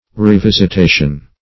Revisitation \Re*vis`it*a"tion\, n. The act of revisiting.